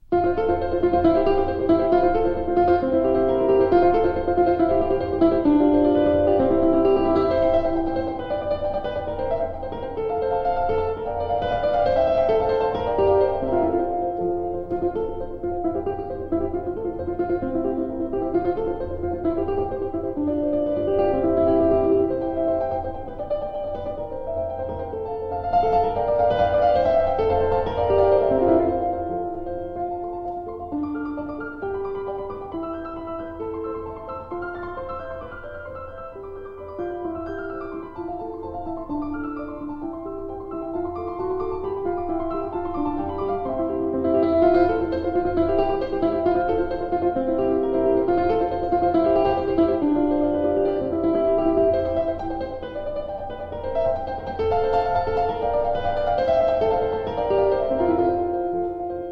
Piano
L'enregistrement a été effectué dans l’auditorium de l’Abbaye aux Dames de SAINTES.